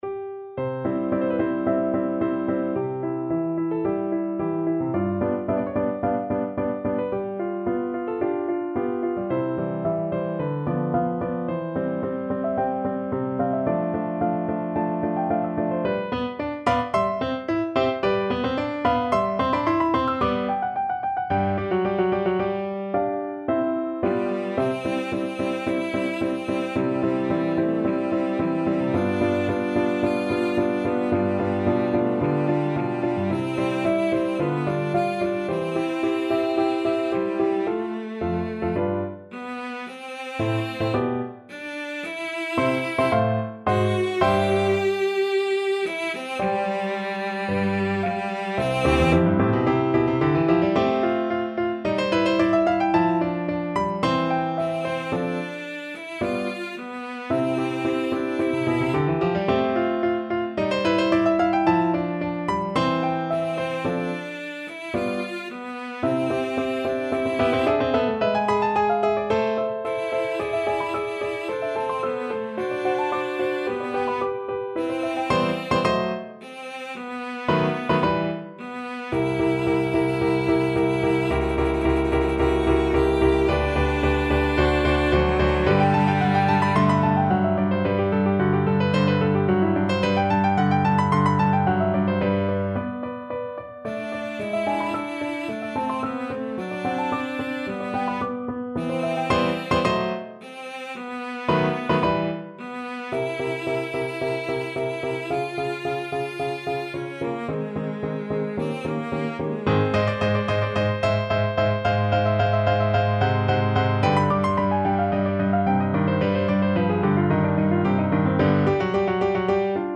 Classical Haydn, Franz Josef In Native Worth from 'The Creation' Cello version
Cello
C major (Sounding Pitch) (View more C major Music for Cello )
4/4 (View more 4/4 Music)
Andante =110
Classical (View more Classical Cello Music)